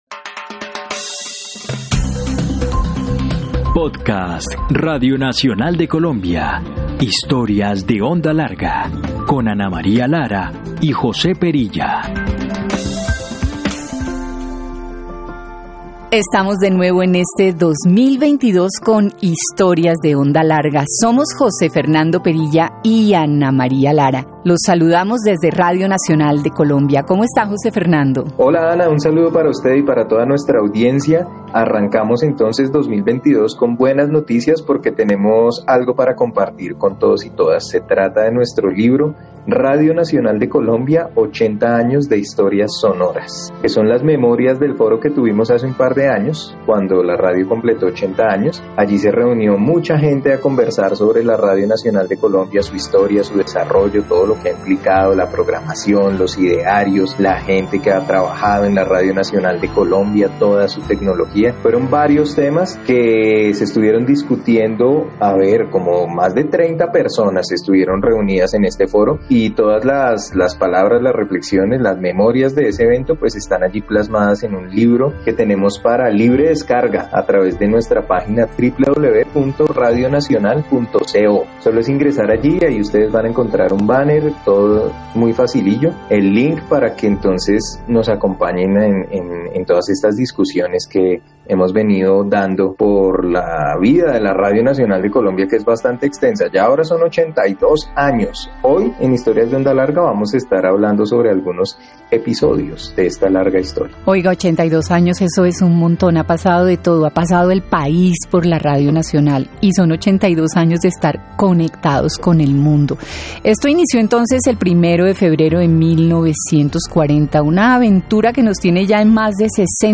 Un breve resumen que trae a memoria momentos cruciales y anécdotas maravillosas de lo que ha sido la historia de la Radio Nacional de Colombia, este año 2022 llegó a sus 82 años. Hoy podemos recordar esas memorias gracias al archivo sonoro existente.